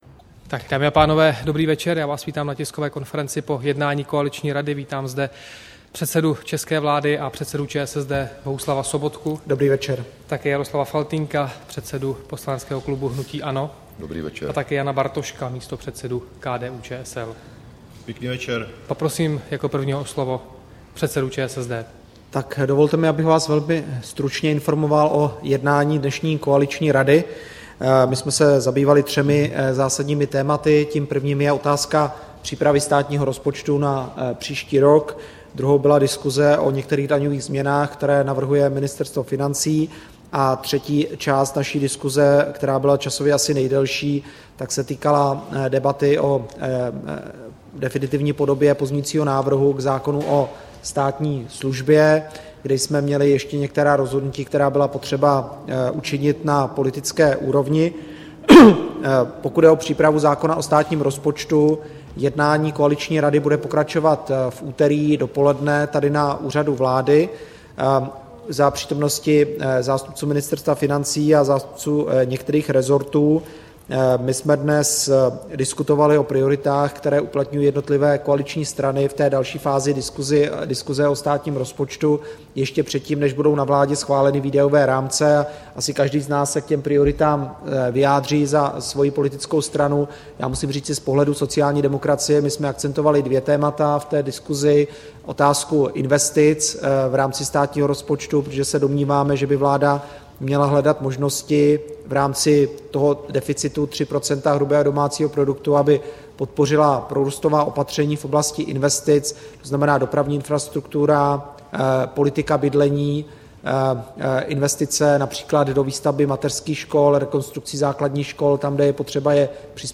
Tisková konference po jednání koaliční rady, 21. května 2014